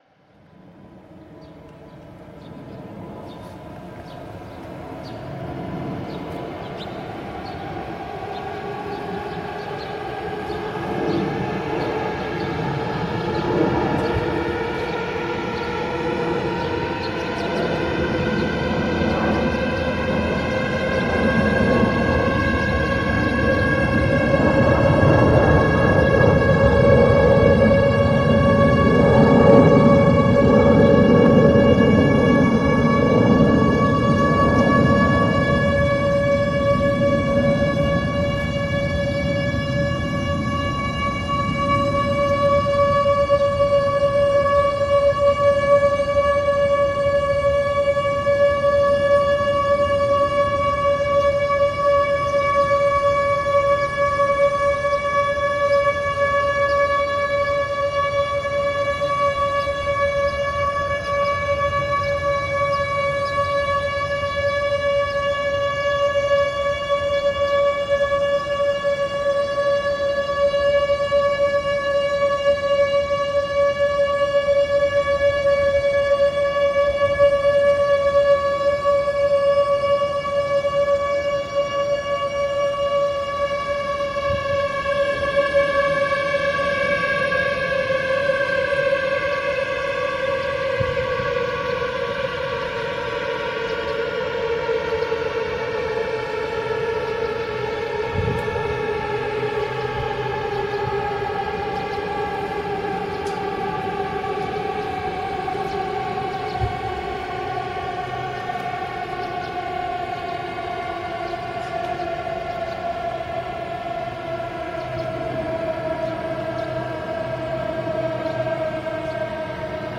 sirene-flugzeuge.mp3